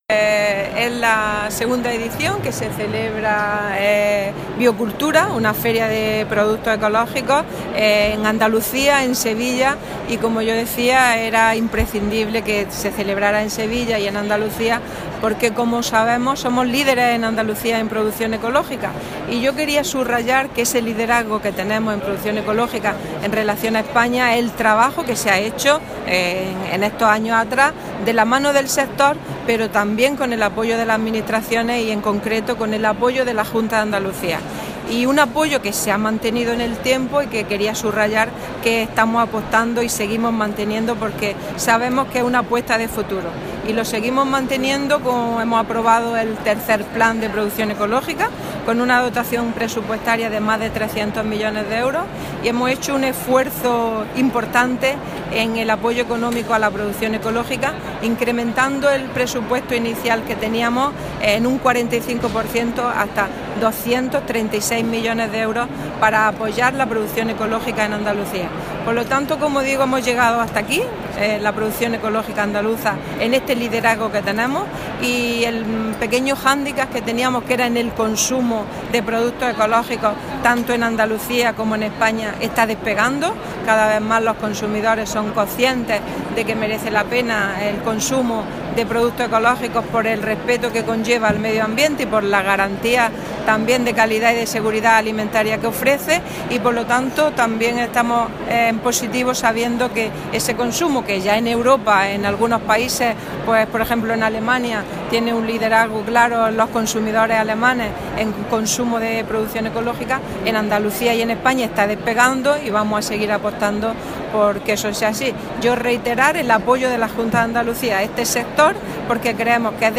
La consejera de Agricultura, Carmen Ortiz, ha inaugurado en Sevilla la II Feria Internacional de Productos Ecológicos y Consumo Responsable, BioCultura
Declaraciones de Carmen Ortiz sobre BioCultura